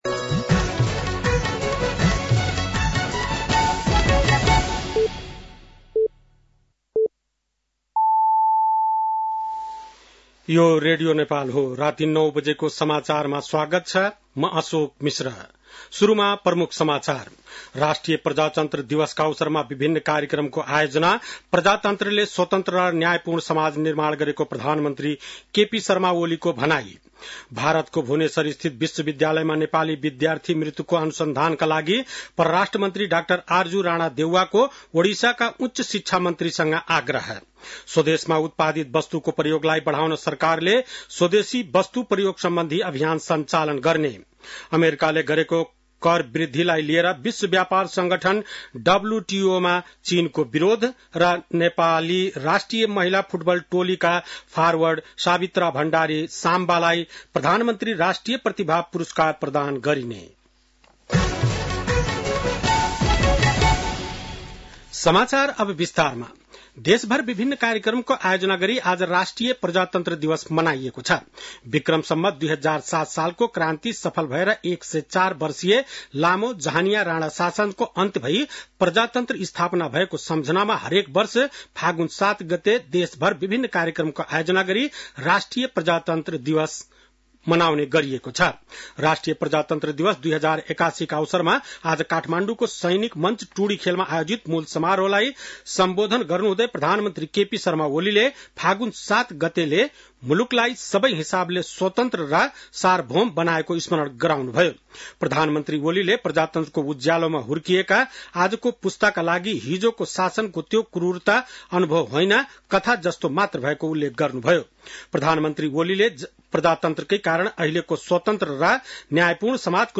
बेलुकी ९ बजेको नेपाली समाचार : ८ फागुन , २०८१